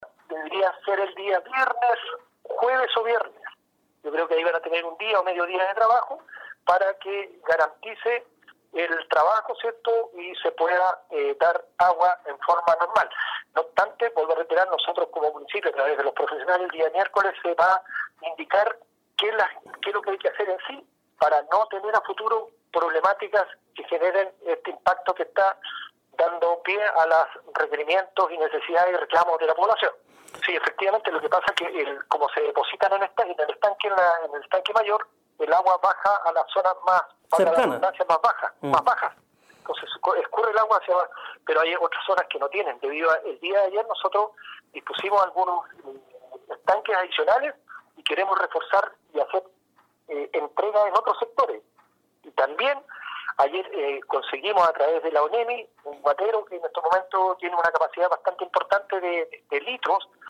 11-ALCALDE-QUINCHAO-2.mp3